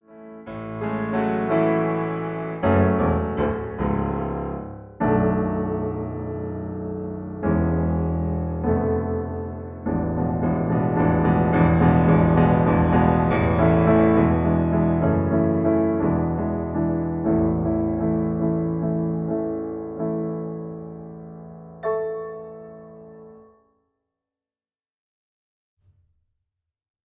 In B-flat. Piano accompaniment